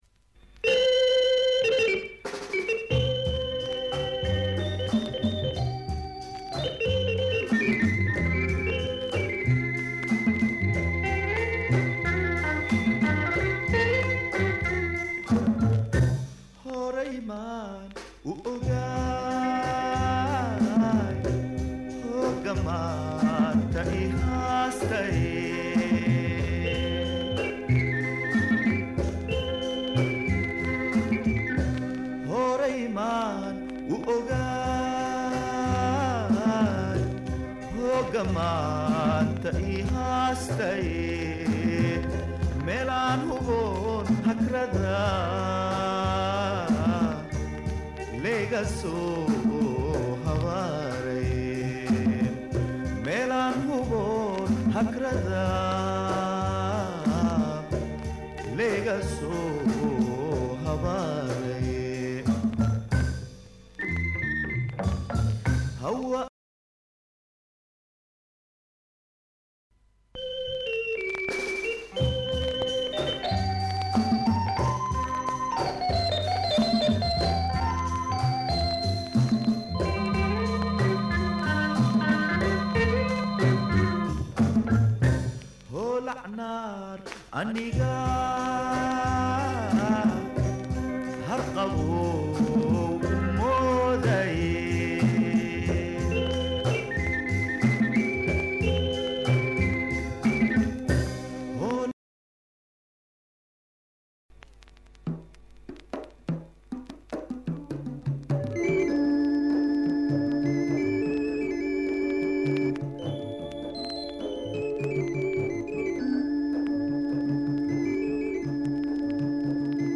Somali traditional songs
organ grind